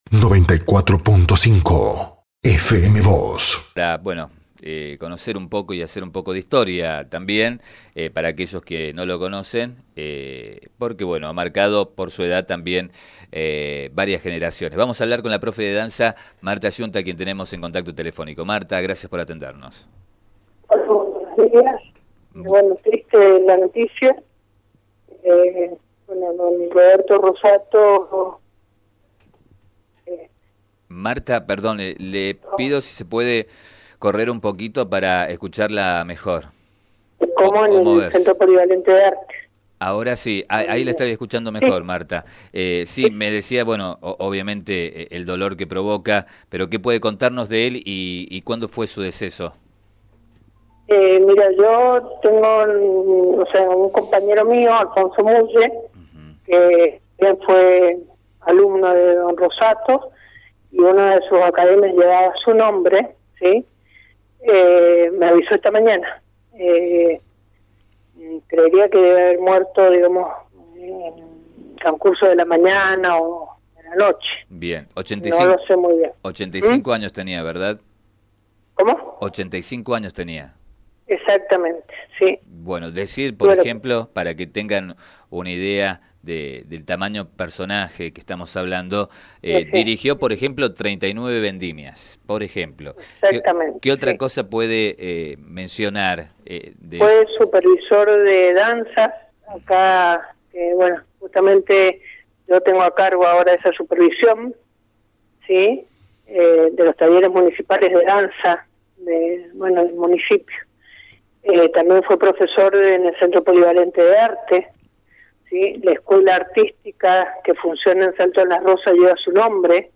dialogó con FM Vos (94.5) y con Diario San Rafael